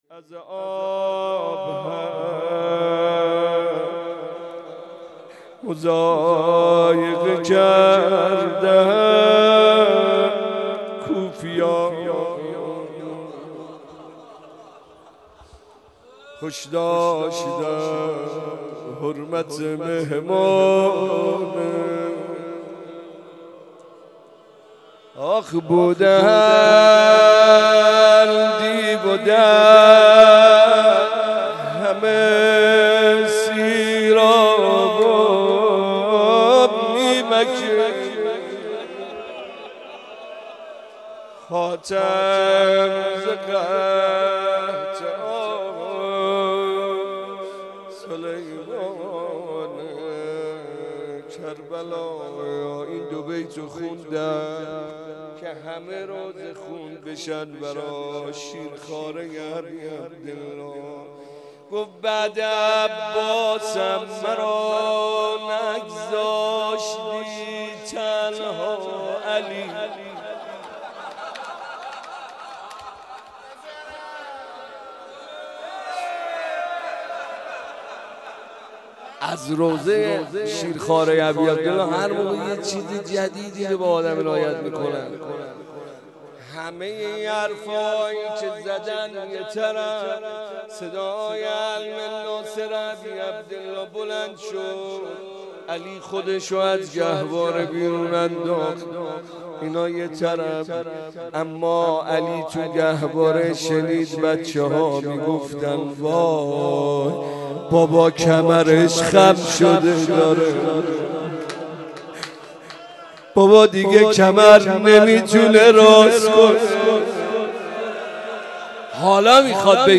02.rozeh.mp3